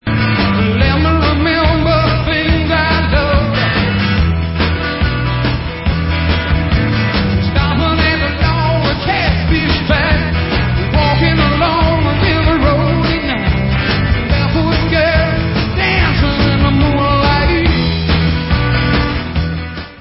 Remasterovaná verze živého alba z roku 1970